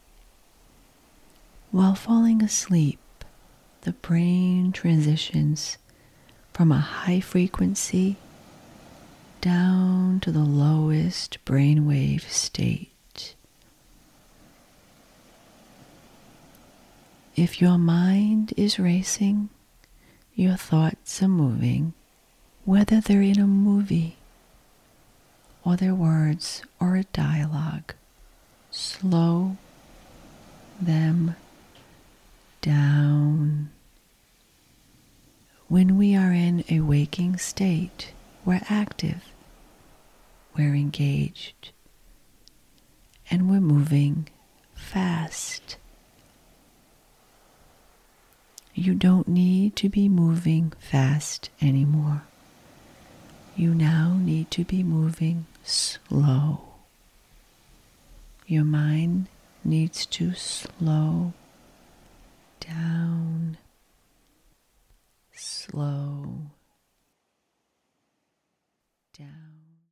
Here are a few clips…some have music in the background, some do not.
Sleep Meditation Clip
clip-sleep-meditation.mp3